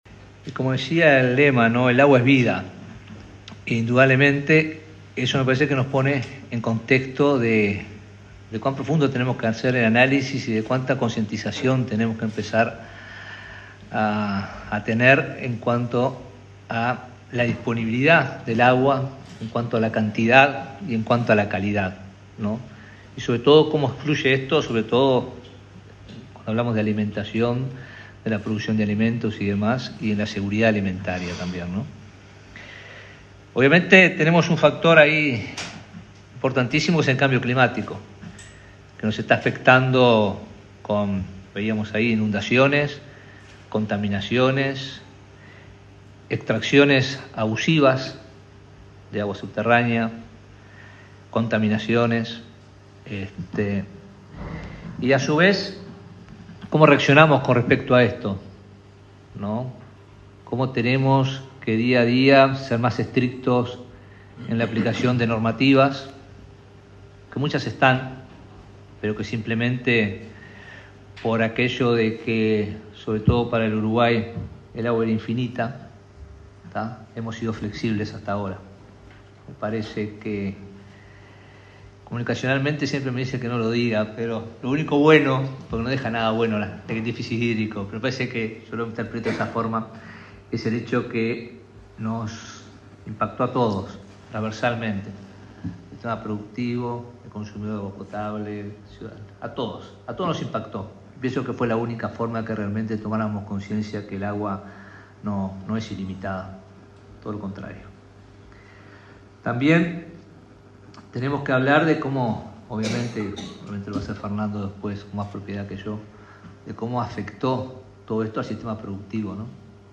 Palabras de autoridades en acto por el Día Mundial de la Alimentación